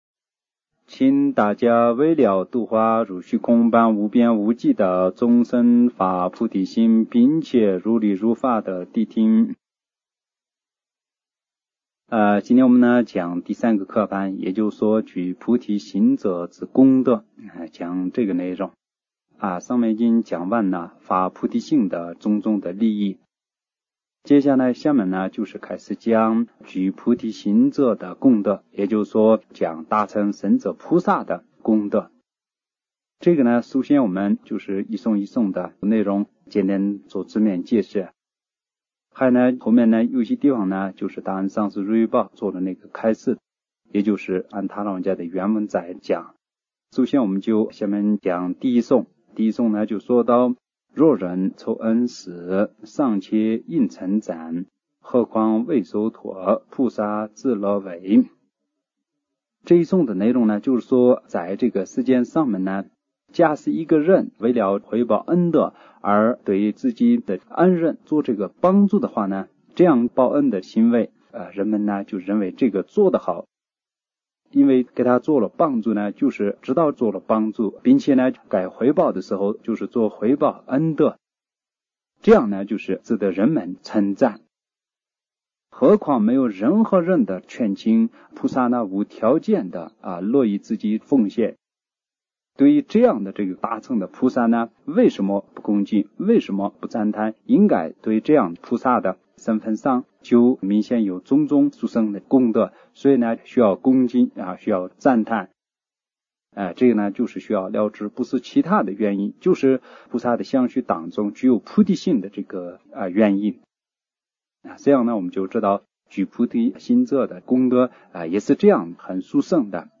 佛學講座-聲音檔